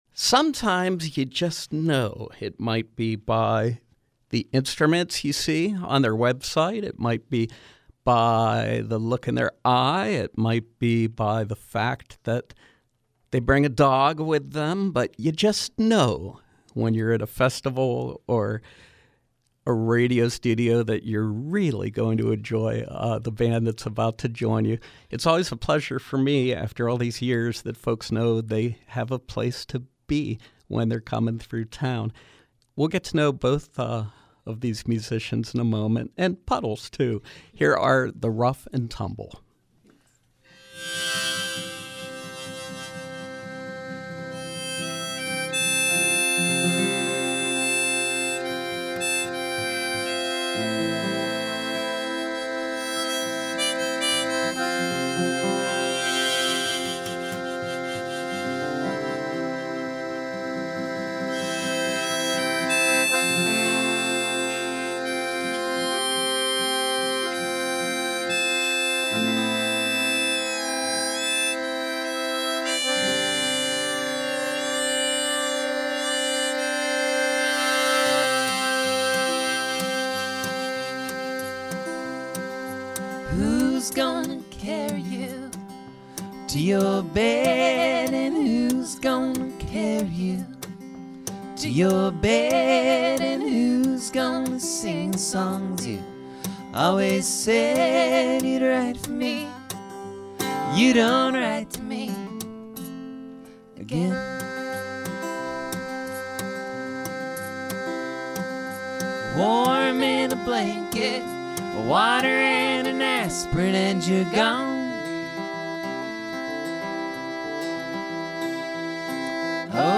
Live music with traveling folk duo